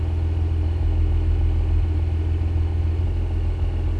rr3-assets/files/.depot/audio/Vehicles/v6_01/v6_01_idle.wav
v6_01_idle.wav